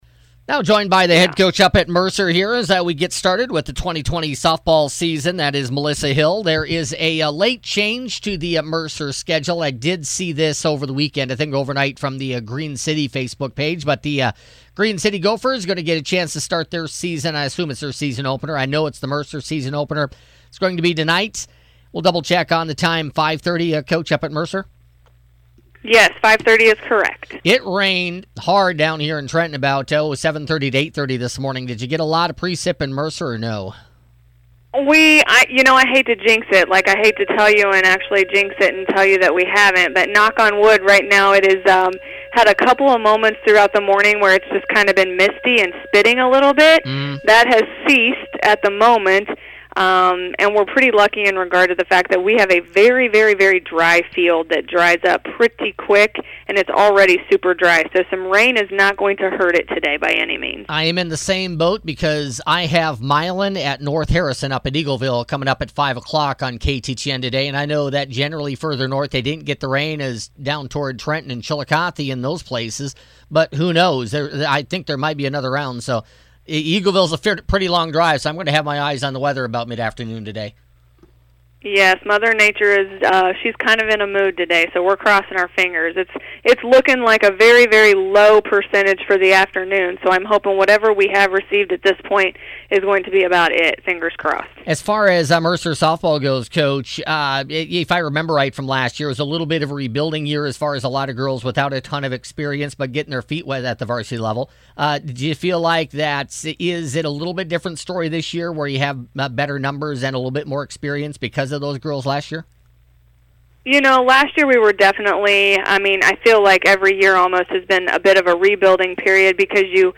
Mercer softball coaches show for August 31, 2020